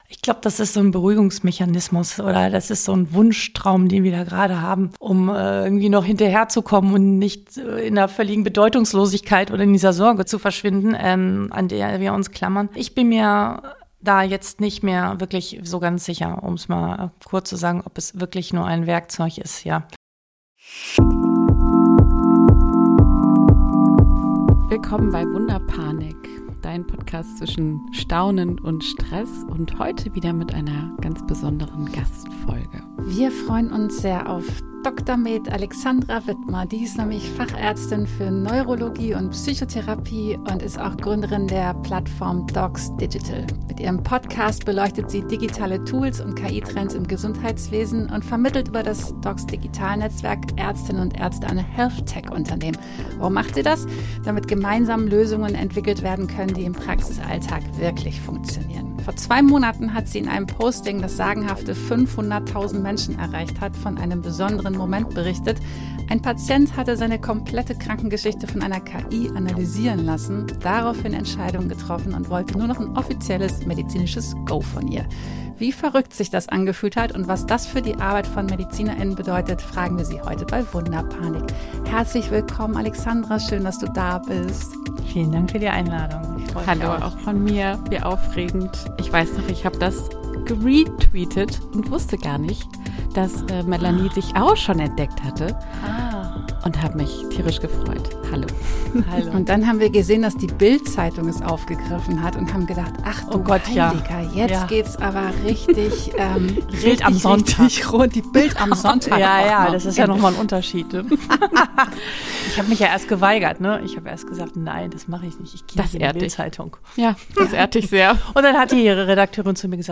Wie immer auch mit einem bisschen Humor.